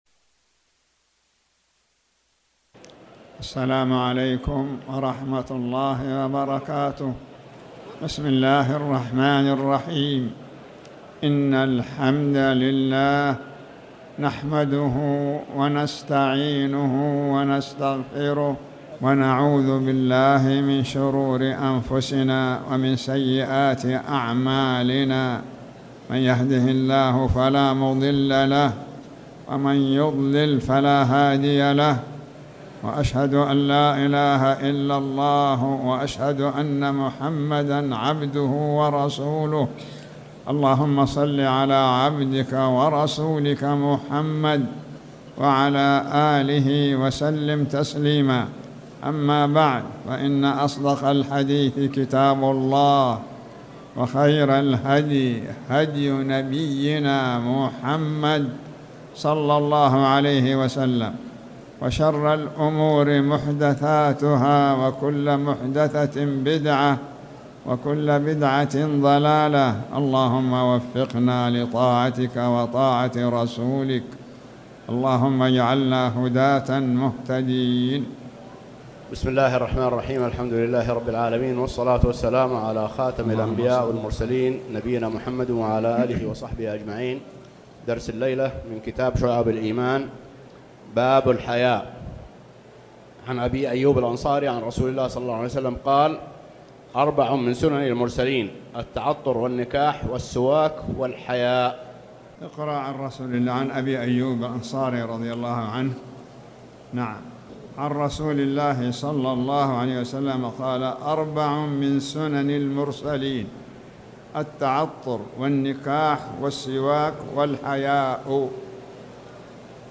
تاريخ النشر ١ ربيع الثاني ١٤٣٩ هـ المكان: المسجد الحرام الشيخ